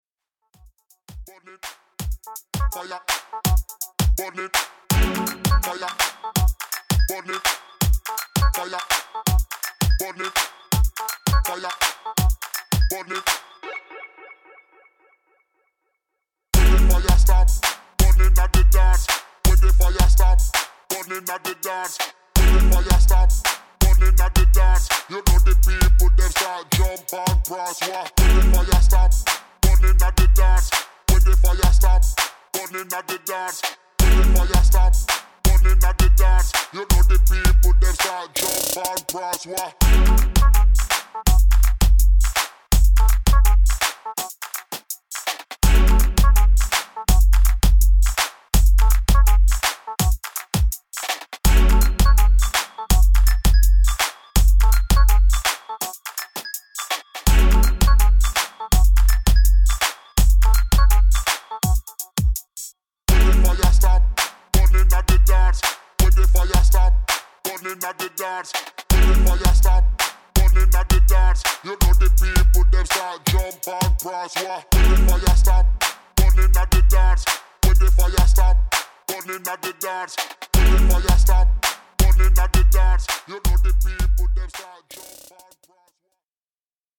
[ DRUM'N'BASS / JUNGLE / BASS ]